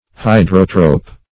Search Result for " hydrotrope" : The Collaborative International Dictionary of English v.0.48: Hydrotrope \Hy"dro*trope\, n. [Hydro-, 1 + Gr.